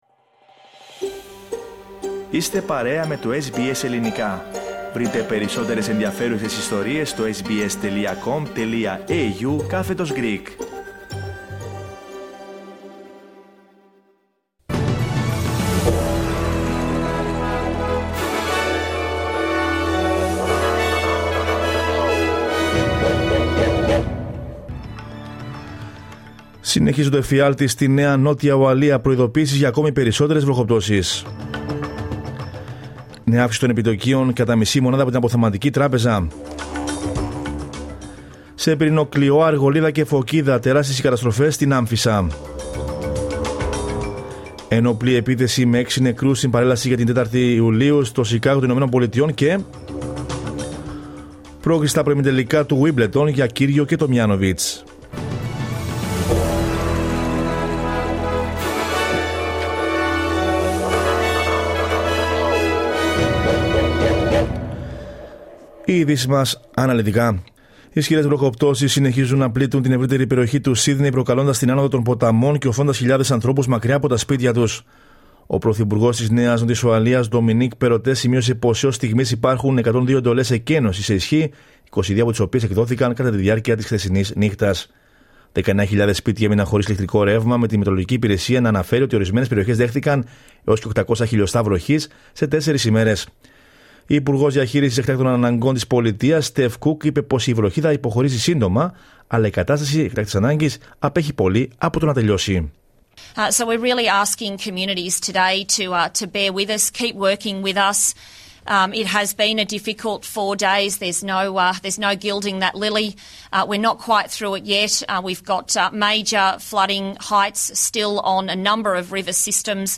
News in Greek from Australia, Greece, Cyprus and the world is the news bulletin of Tuesday 5 July 2022.